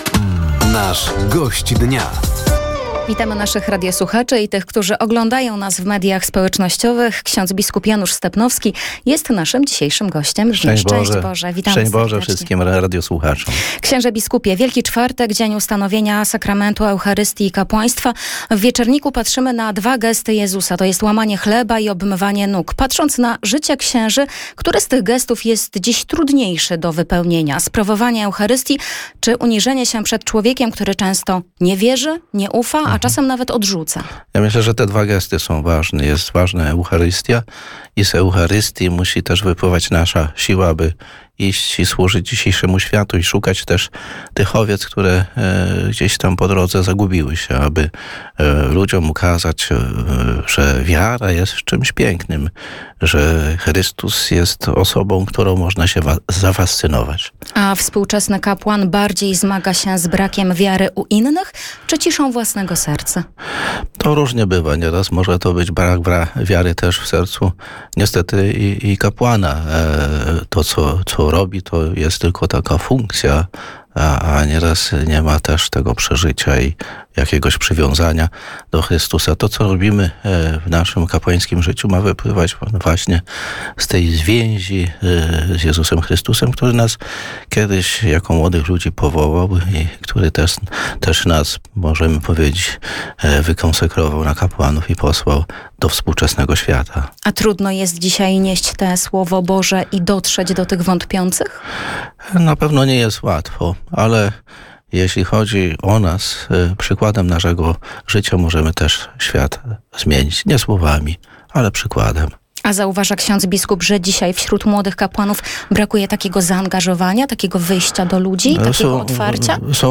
Gościem Dnia Radia Nadzieja był bp Janusz Stepnowski. Ordynariusz Diecezji Łomżyńskiej mówił o roli kapłana we współczesnym świecie, dotarciu ze Słowem Bożym do młodych, a także wyzwaniach w związku z coraz mniejszą liczbą księży.